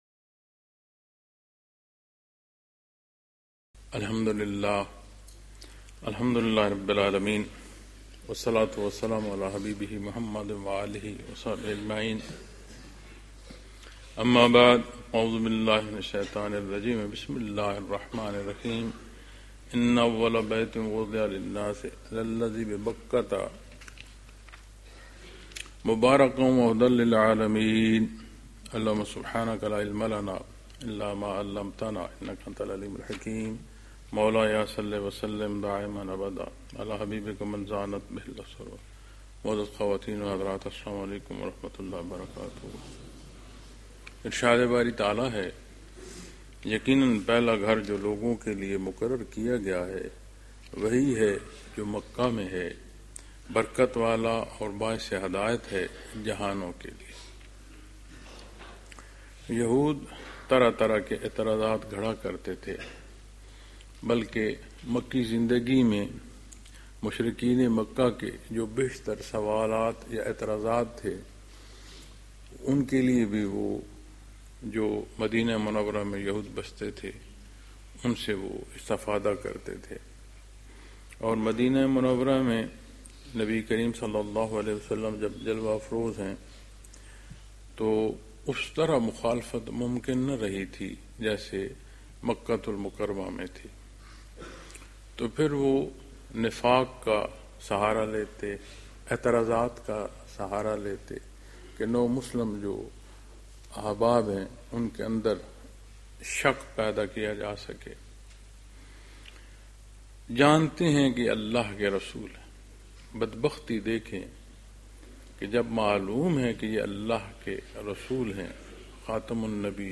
Lectures in Munara, Chakwal, Pakistan on November 9,2025